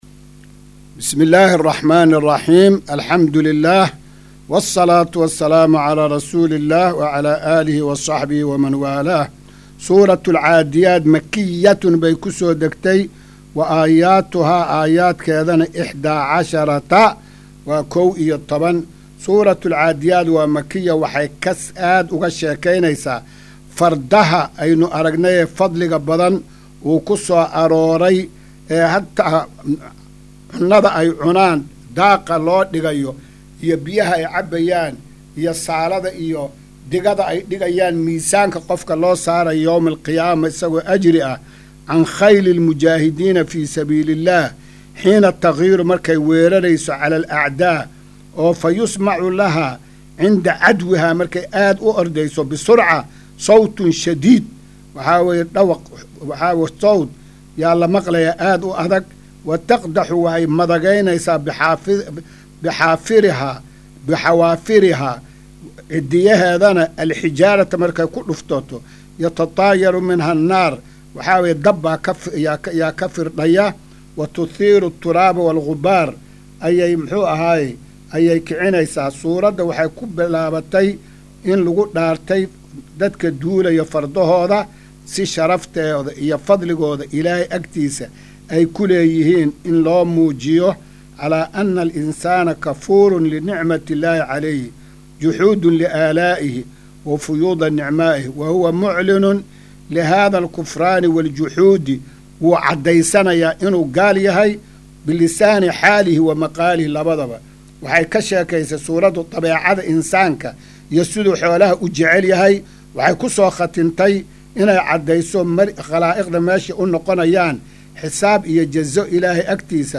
Maqal:- Casharka Tafsiirka Qur’aanka Idaacadda Himilo “Darsiga 290aad”